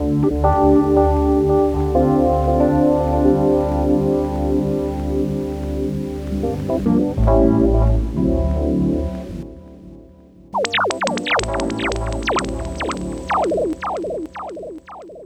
Ala Brzl 2 Rhodez-G.wav